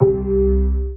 ORGAN-09.wav